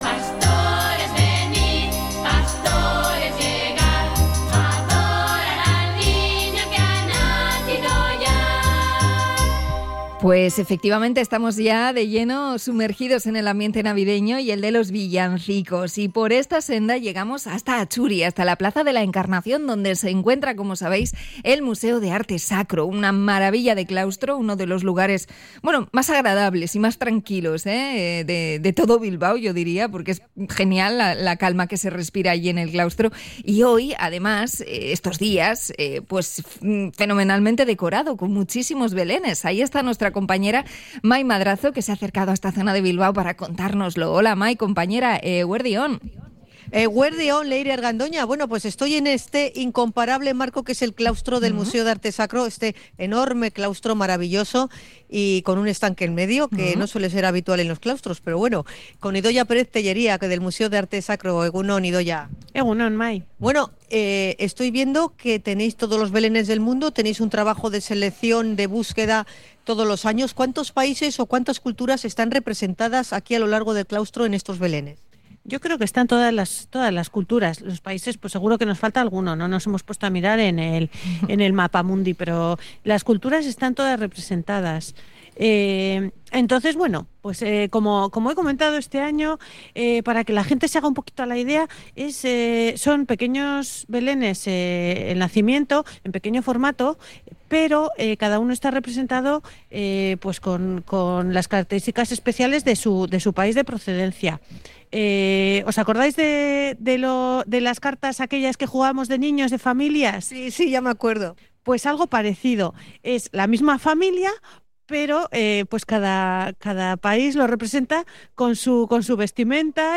Podcast Cultura